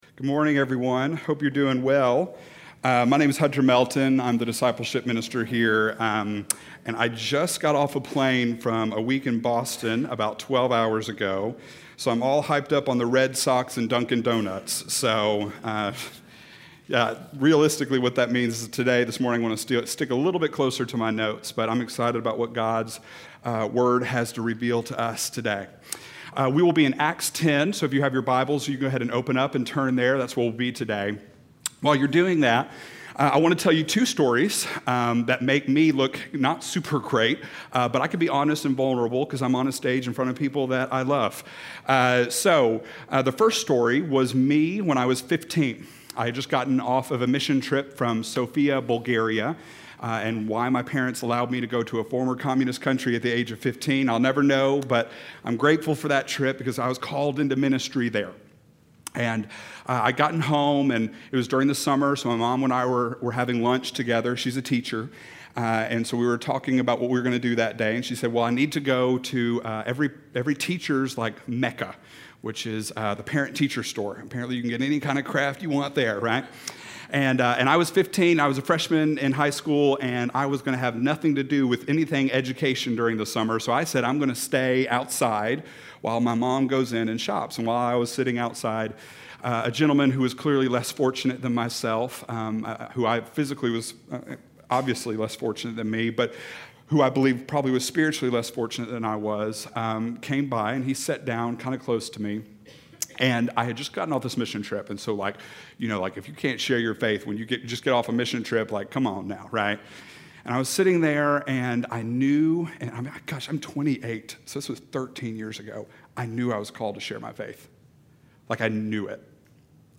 Two Sides of the Story - Sermon - Avenue South